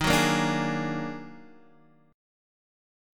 D#mM11 chord